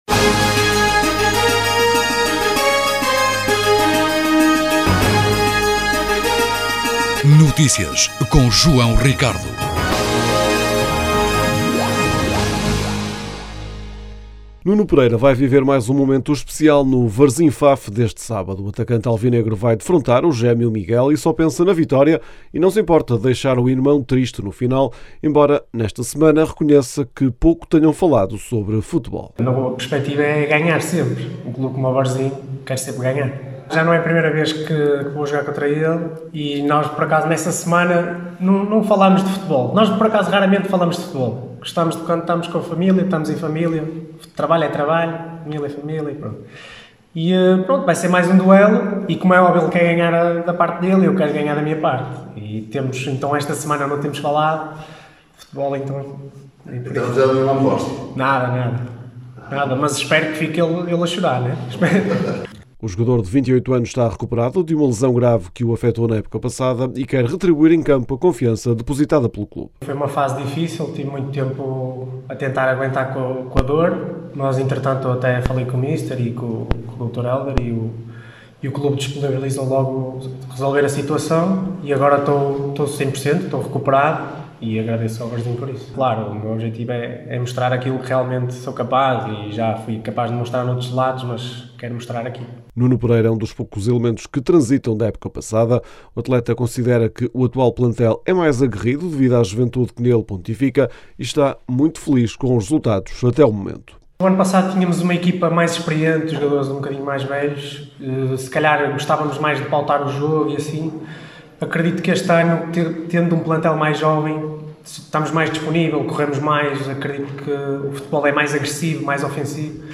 O novo treinador foi esta sexta-feira à sala de imprensa explicar que a ambição dos responsáveis pelo emblema da caravela foi determinante para aceitar liderar o projeto. Apesar de ter apenas dois dias de trabalho, Petit já constatou que tem um plantel forte com um misto de juventude e experiência, tendo como propósito potenciar a qualidade existente e instalar uma mentalidade ganhadora.